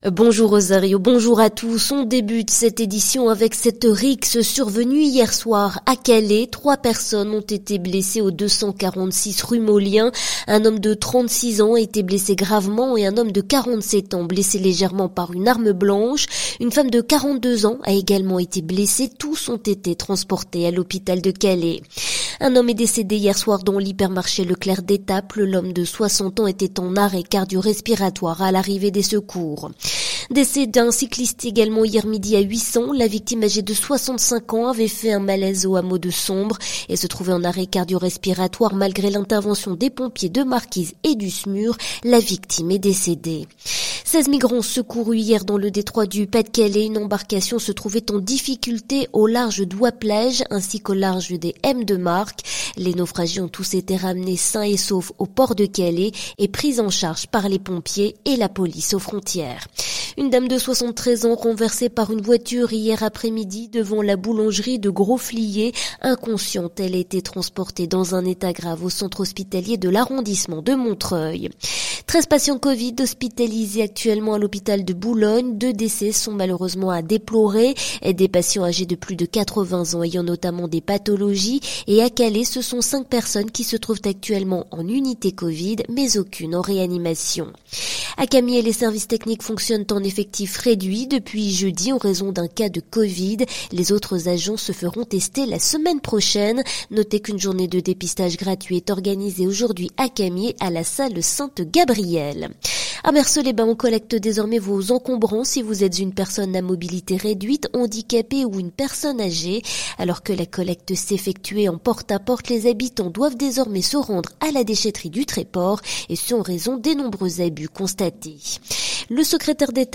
Le journal du samedi 10 octobre sur la Côte d'Opale et la Côte Picarde